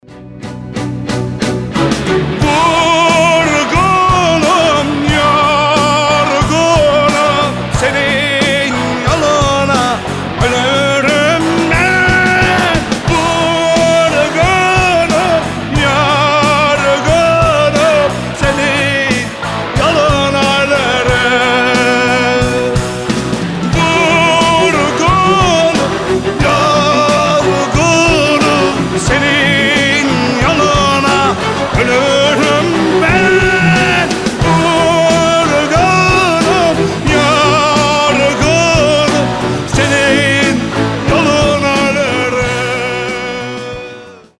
• Качество: 320, Stereo
мужской голос
грустные
сильные